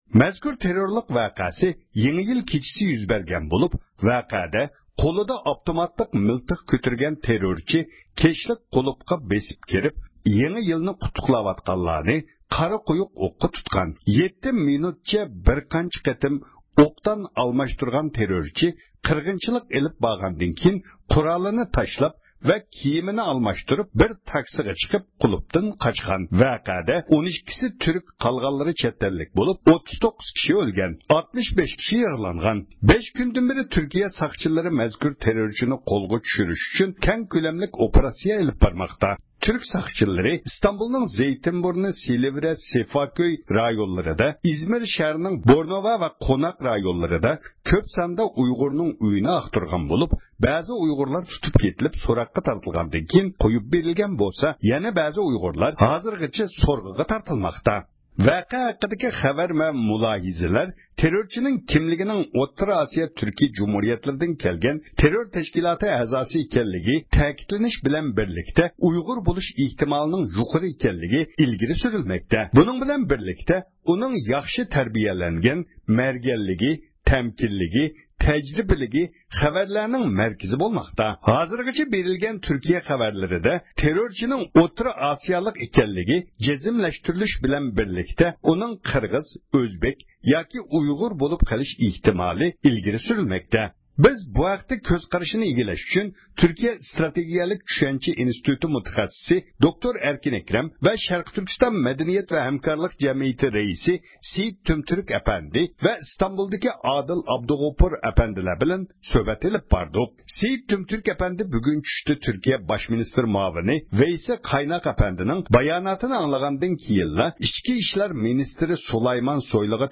سۆھبەت ئېلىپ باردۇق.